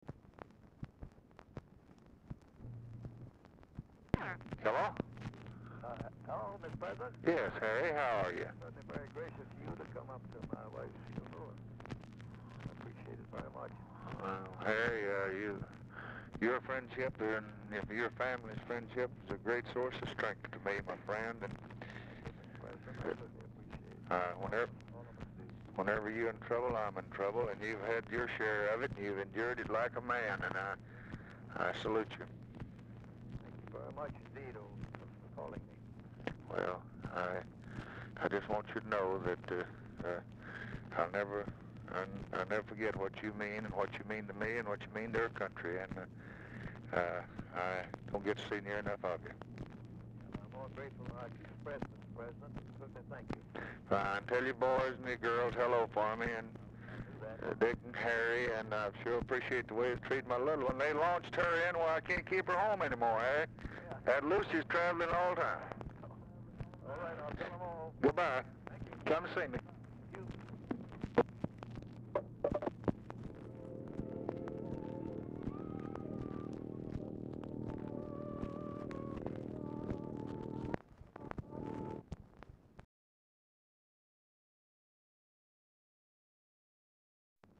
Telephone conversation
Dictation belt
Oval Office or unknown location